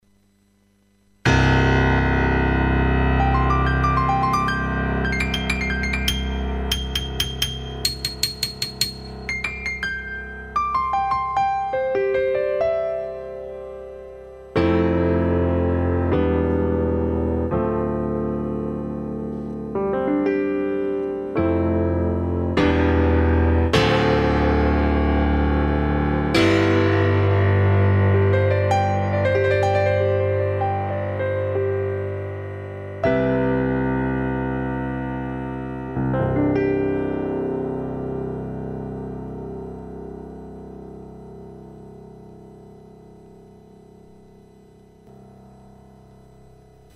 gi-test1 studio grand preset2.mp3